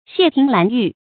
謝庭蘭玉 注音： ㄒㄧㄝ ˋ ㄊㄧㄥˊ ㄌㄢˊ ㄧㄩˋ 讀音讀法： 意思解釋： 比喻能光耀門庭的子侄。